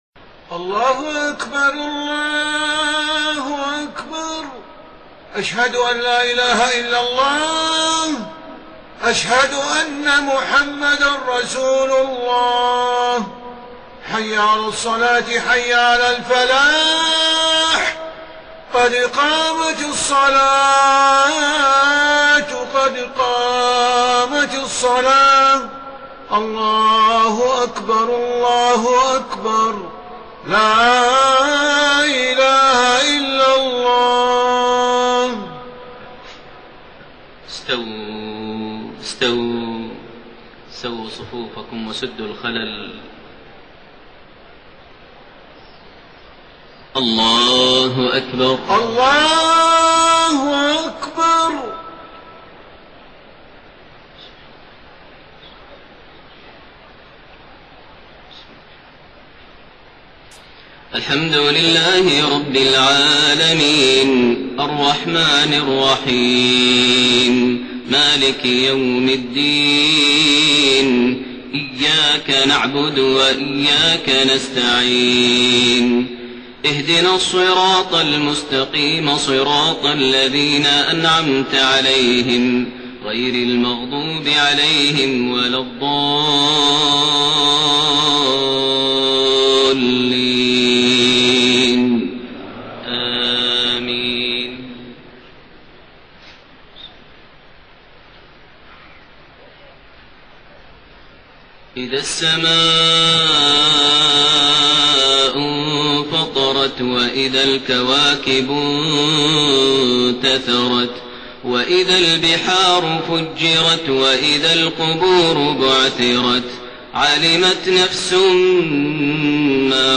صلاة المغرب 14 ذو الحجة 1432هـ سورة الانفطار > 1432 هـ > الفروض - تلاوات ماهر المعيقلي